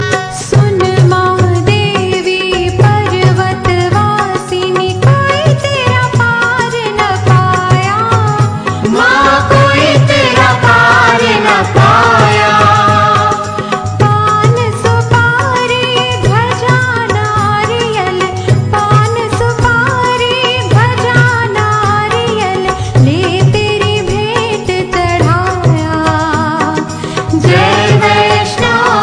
CategoryDevotional Ringtones